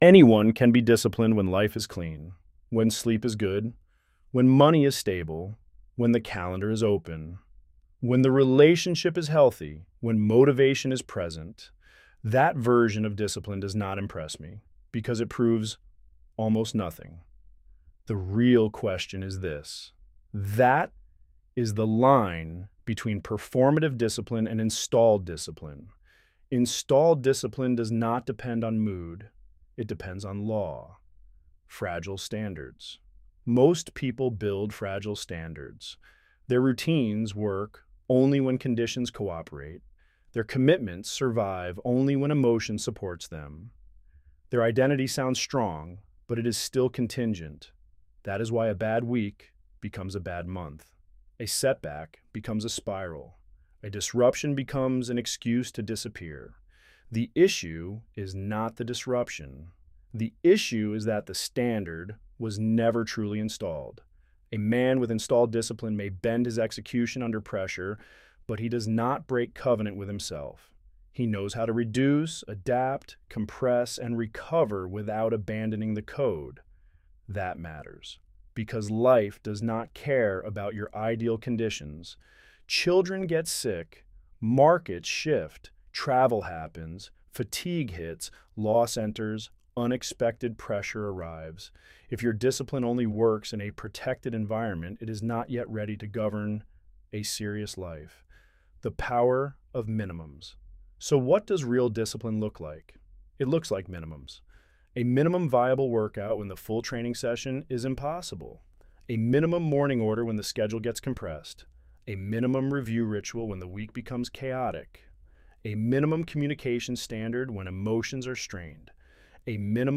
Narrated by the Author Download narration Anyone can be disciplined when life is clean.